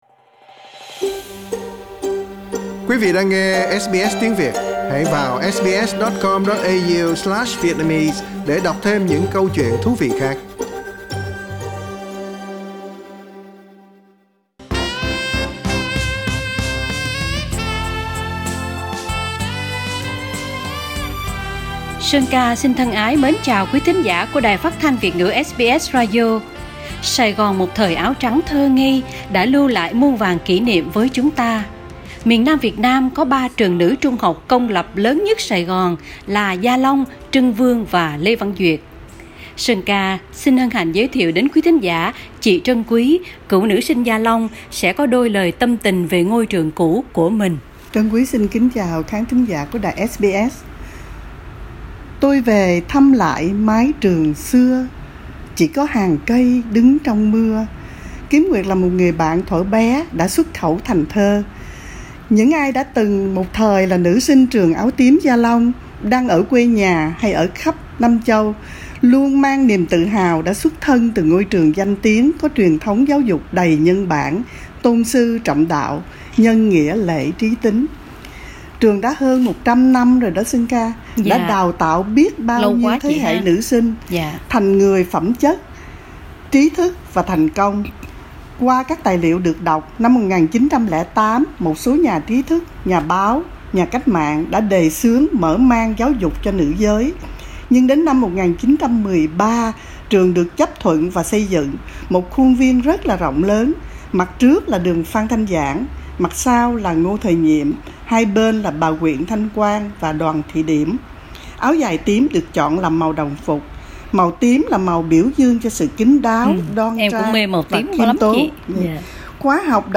hai cựu nữ sinh nhớ về ba ngôi trường nữ nổi tiếng nhất ở Sài Gòn trước 75 và những bản nhạc sáng tác cho những tà áo làm biết bao nam sinh thời đó ngẩn ngơ..